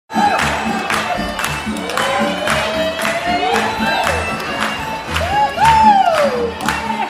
Le spectacle de Noël de l’église de Blue Sea a eu lieu dimanche soir. À cette occasion, le groupe Tria a fredonné des cantines du temps des fêtes accompagné des enfants de l’école alternative de Blue Sea.
clip-1-spectacle-de-noel-16-decembre-am.mp3